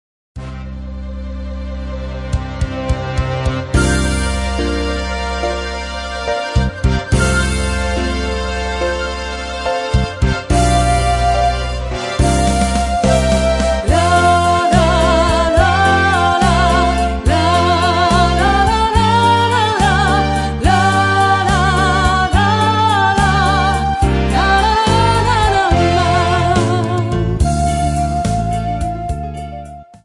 Folk Tangos and Waltzes.